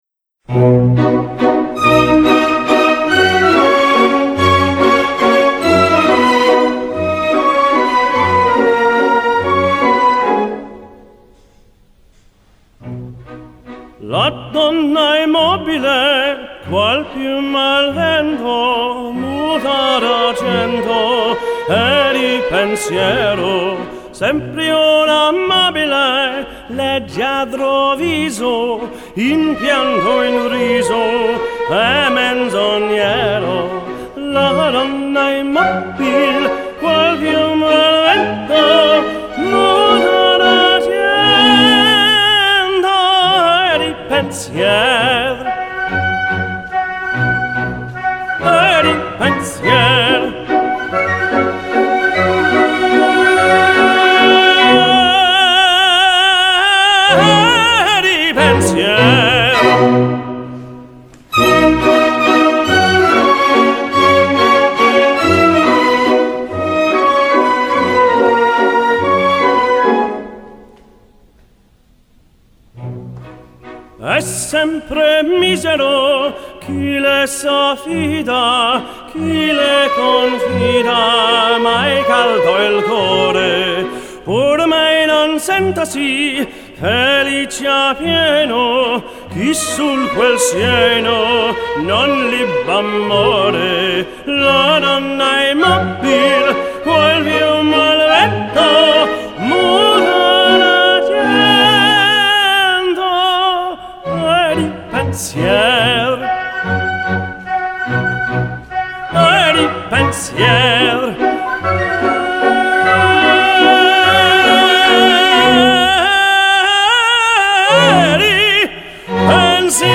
Singing Master of Ceremonies First & Foremost Entertainment Ltd